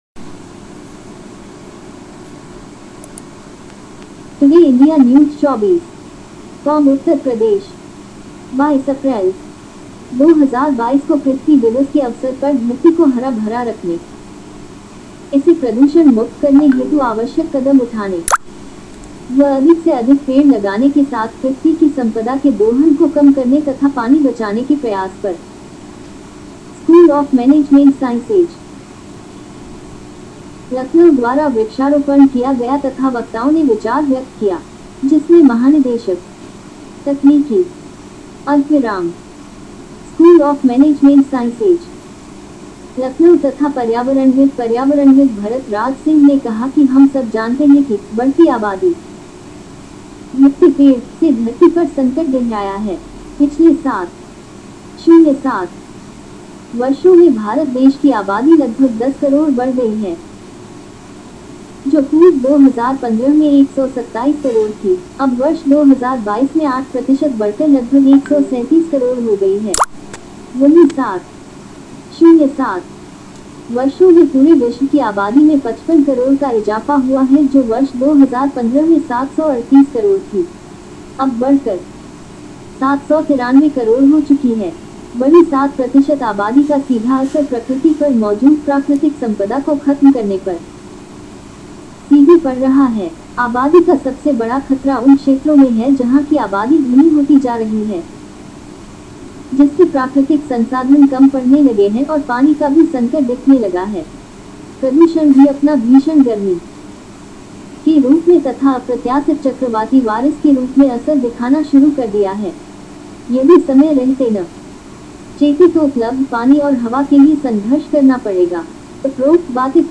Radio .